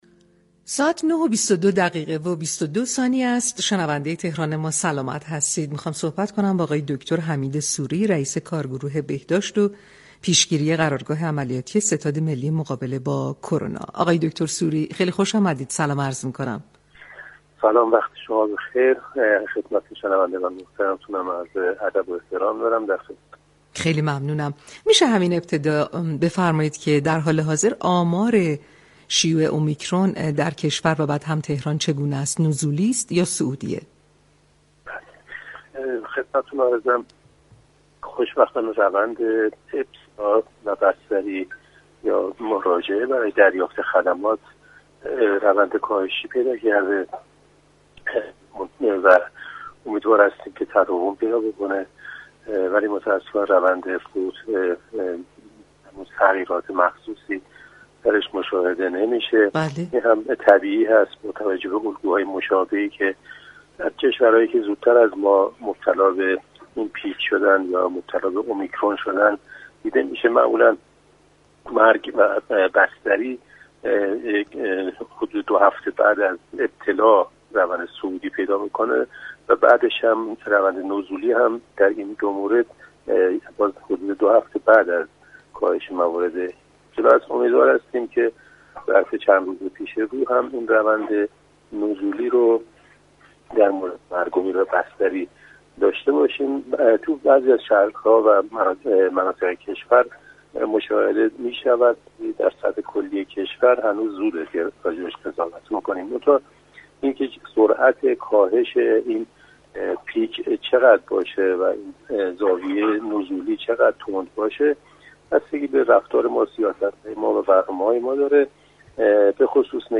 به گزارش پایگاه اطلاع رسانی رادیو تهران، دكتر حمید سوری رئیس كارگروه بهداشت و پیشگیری قرارگاه عملیاتی ستاد ملی مقابله با كرونا در گفتگو با برنامه تهران كلینیك 8 اسفندماه با اظهار خرسندی از اینكه روند مراجعه بیماران سرپایی و بستری‌ها نزولی شده گفت: اما متاسفانه آمار مرگ و میر هنوز كاهشی نشده چون مرگ و میرها معمولا دوهفته پس از افزایش بستری ها رخ می‌دهد.